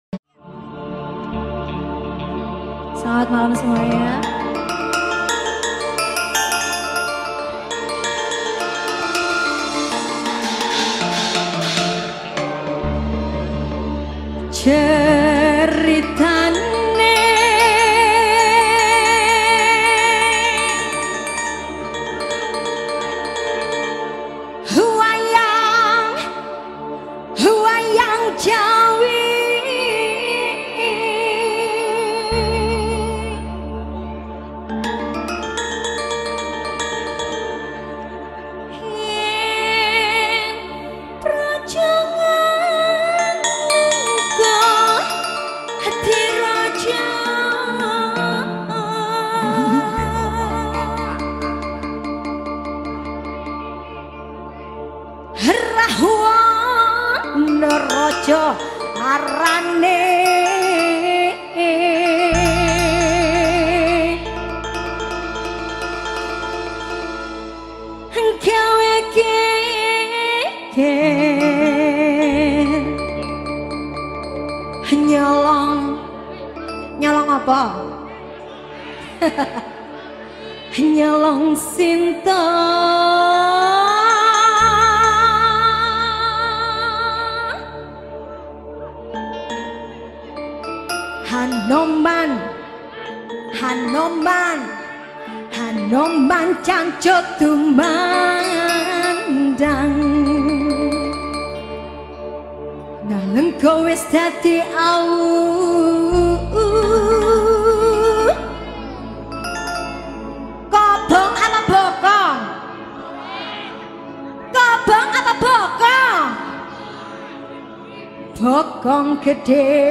penyanyi Dangdut